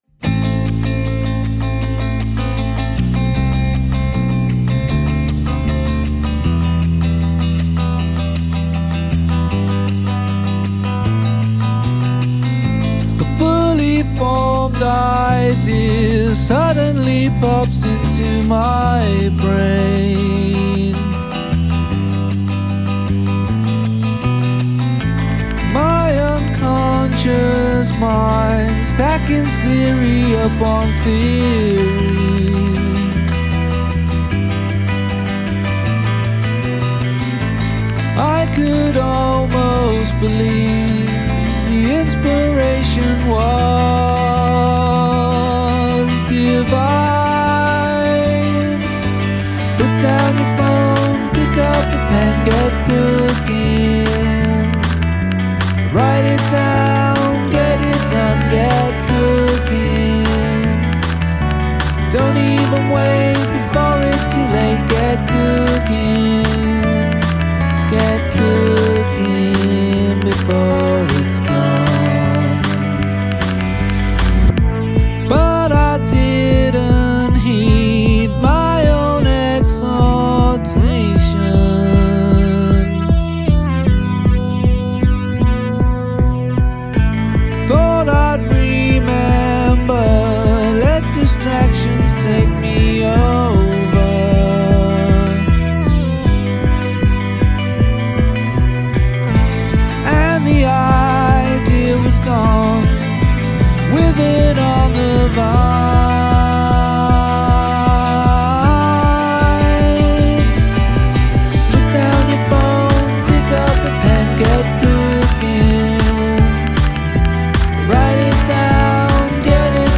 Vocal Harmony is great.
I like the outro guitar.